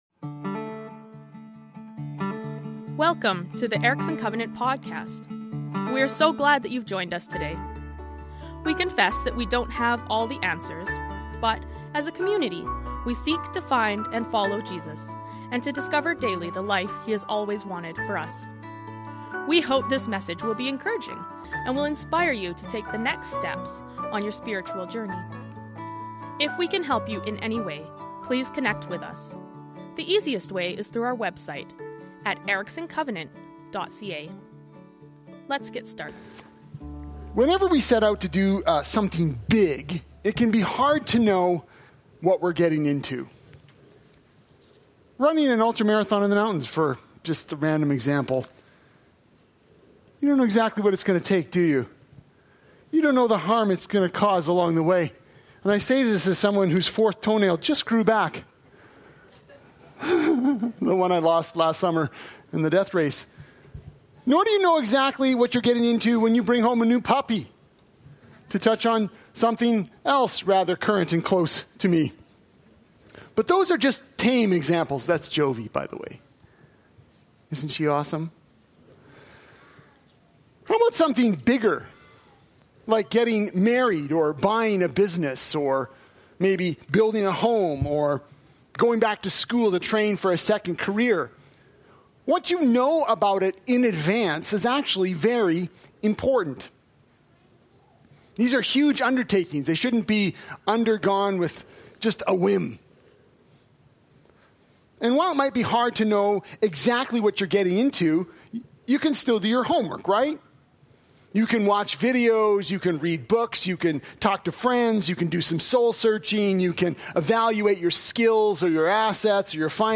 Erickson Covenant Church Sunday Service on June 1, 2025.